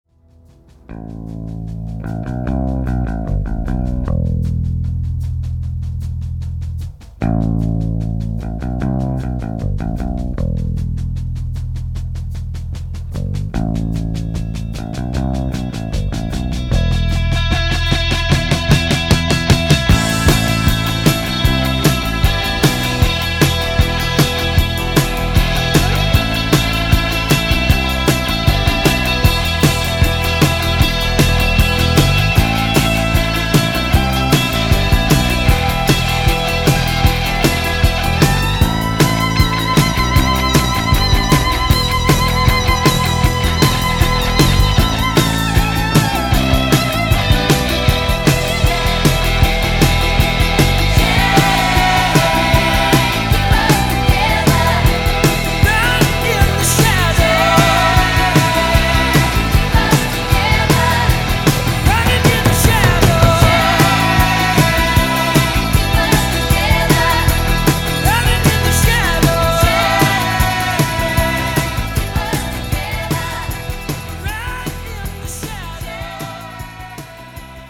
из фильмов
гитара